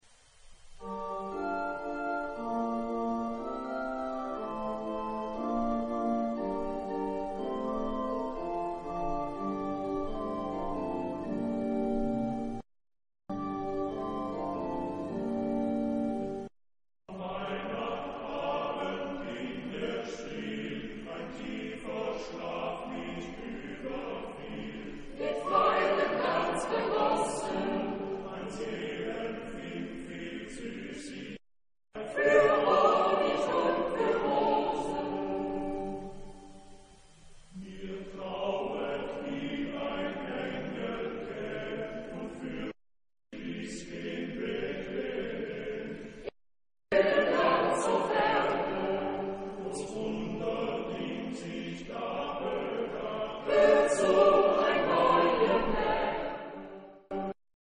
Epoque: 16th century
Genre-Style-Form: Christmas song ; Secular
Type of Choir: SATTBB  (4 mixed voices )
Tonality: G major